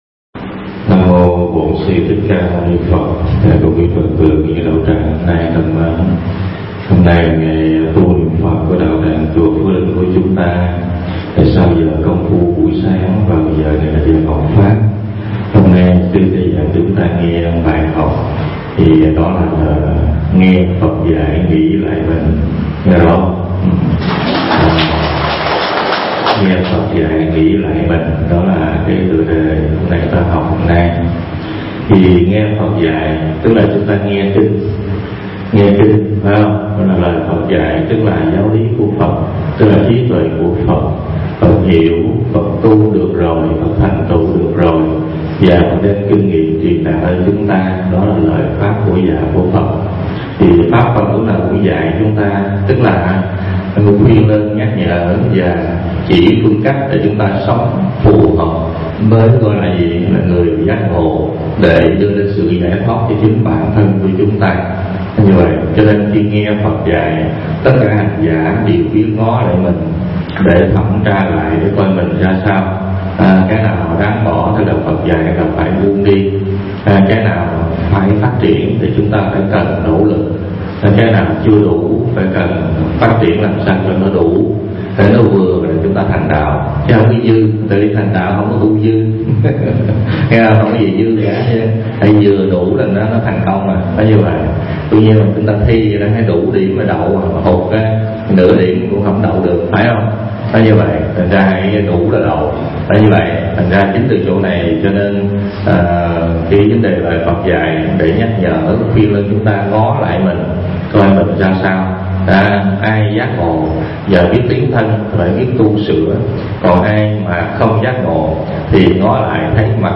Tải mp3 Pháp Âm Nghe Phật Dạy Nghĩ Lại Mình